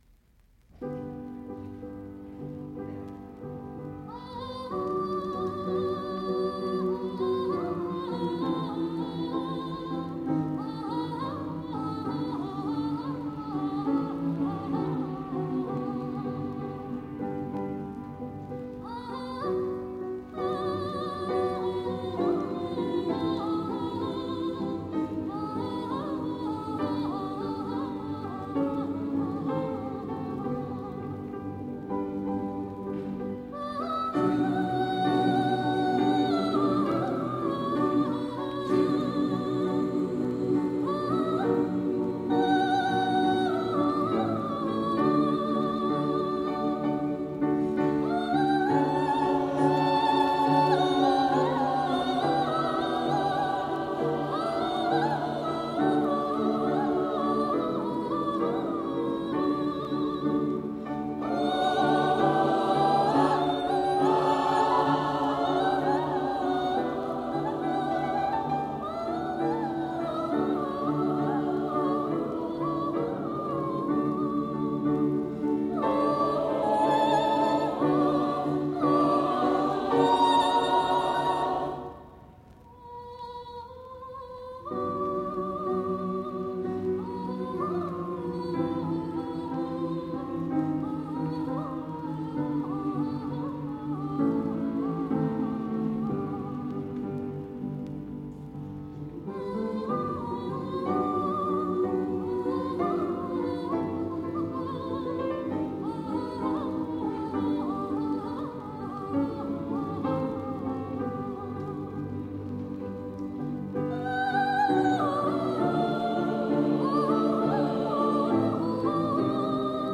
1975 Choir Concert